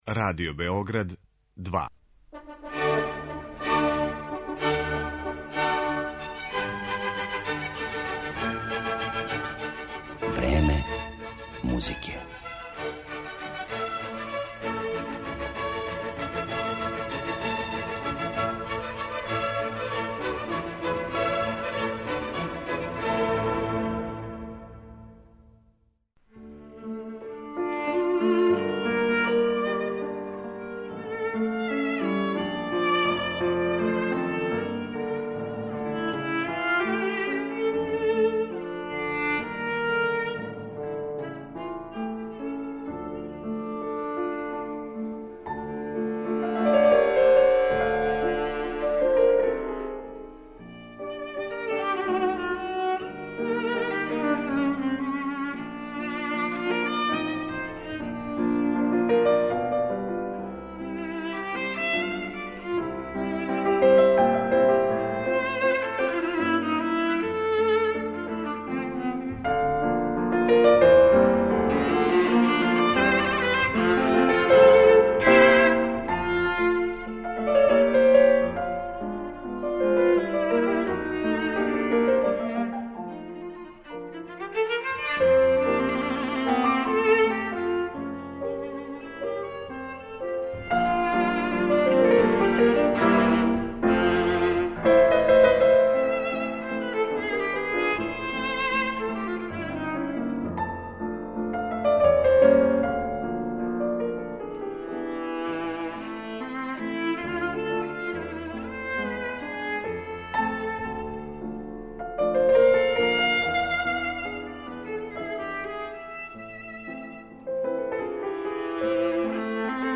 Једном од највећеих виртуоза на виоли данашњице, Табеи Цимерман, посвећена је данашња емисија Време музике у којој ће славна немачка солисткиња изводити композиције Роберта Шумана, Ђерђа Лигетија, Јоханеса Брамса и Фрица Крајслера.